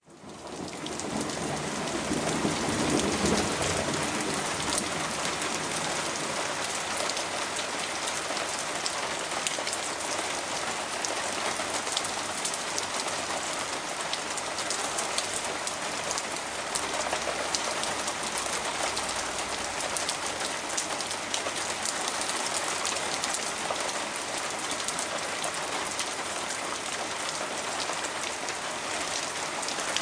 Extended Thunderstorm
A thunderstorm recorded from a doorway at my house with minimal editing, very loud thunder and variable heavy rain.53 minutes long
ALocalStorm.mp3